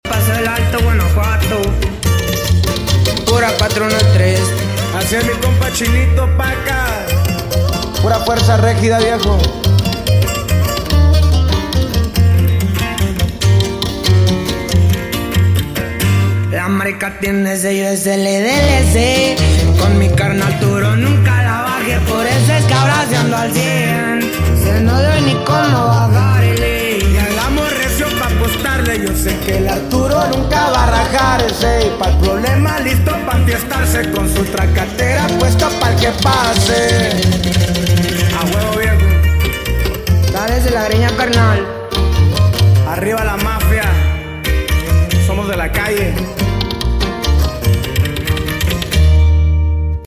8D AUDIO